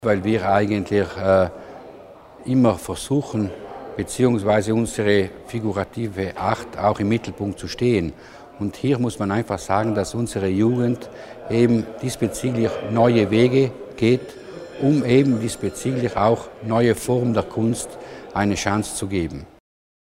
Landesrat Mussner zur Bedeutung von Panoram4